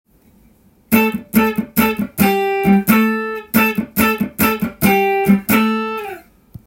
オクターブ奏法でAmペンタトニックスケールを使用した
譜面通り弾いてみました
TAB譜では３連符のフレーズを集めてみました。